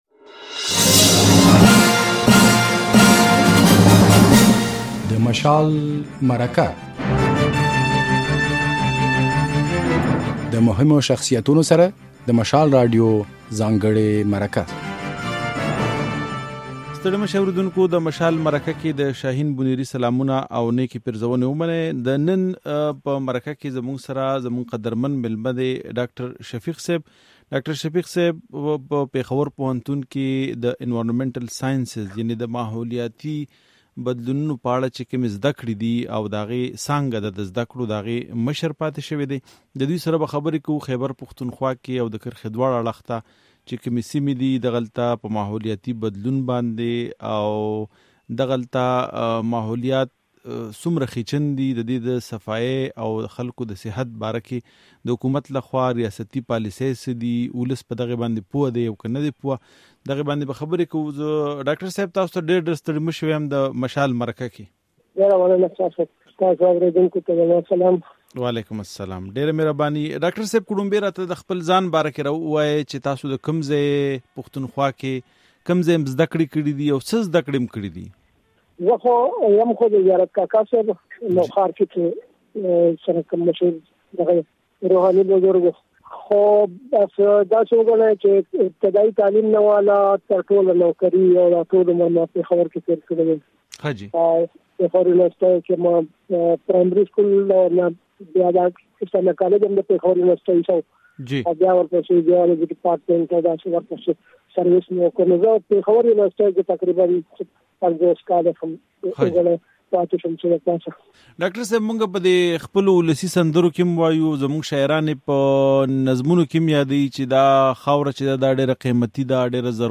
د مشال په مرکه کې